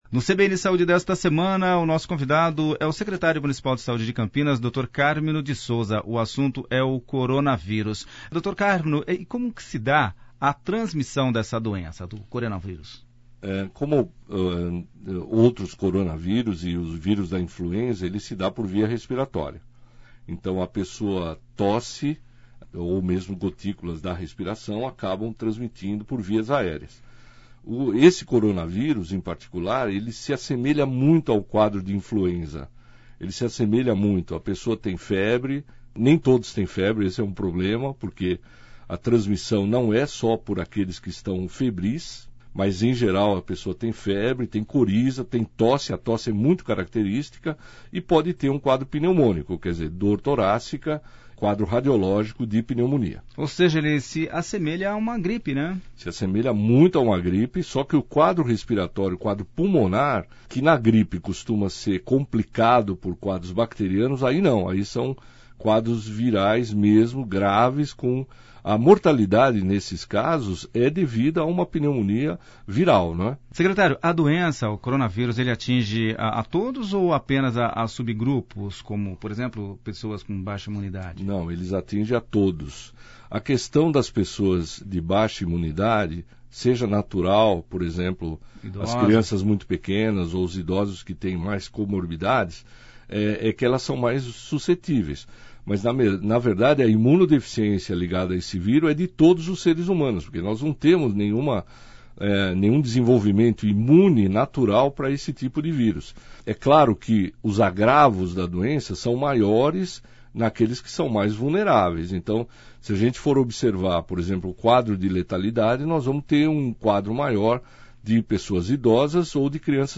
O secretário de saúde de Campinas, Cármino de Sousa, explica todos os detalhes da doença e as medidas tomadas pelo município para combater o novo vírus.